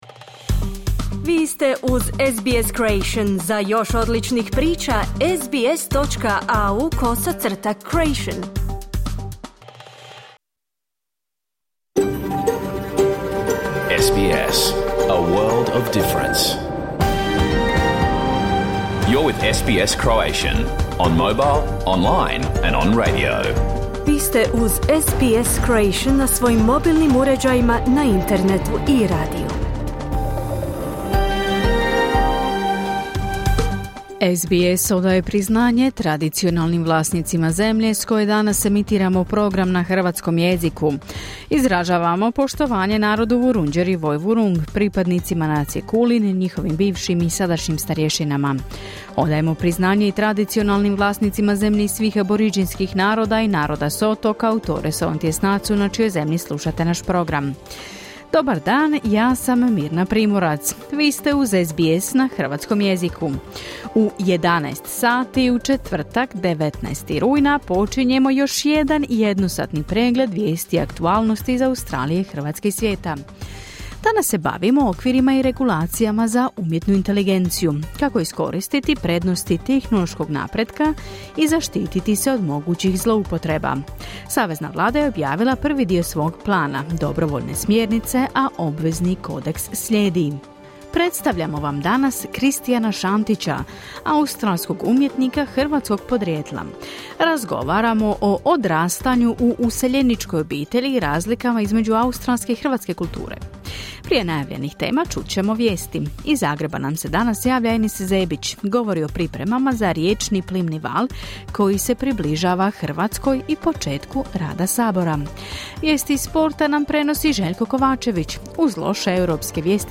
Vijesti i aktualne teme iz Australije, Hrvatske i ostatka svijeta. Program je emitiran uživo na radiju SBS1 u četvrtak, 19. rujna, u 11 sati po istočnoaustralskom vremenu.